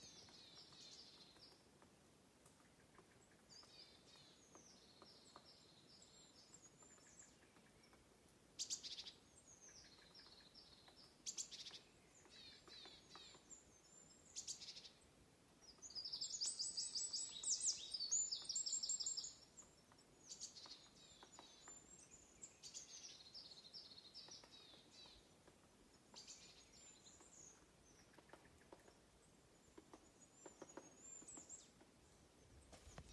Žubīte, Fringilla coelebs
Administratīvā teritorijaValkas novads
StatussDzied ligzdošanai piemērotā biotopā (D)